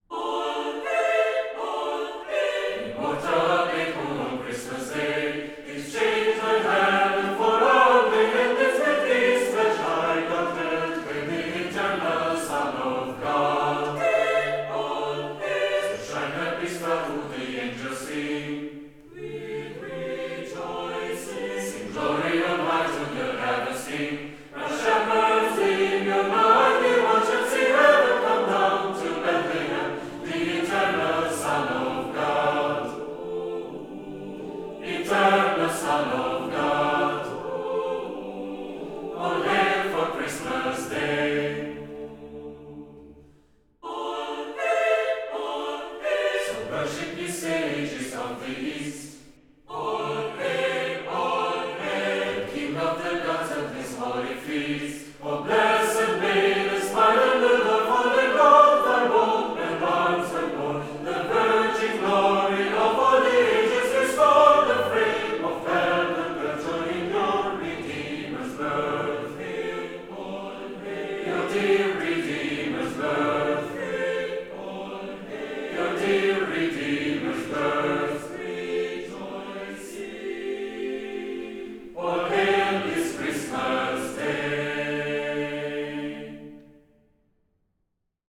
Instrumente - Mixed Choir Tempo - Medium BPM - 85